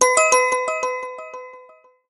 SNSやSMSのメッセージ受信音に最適な長さのサウンドです。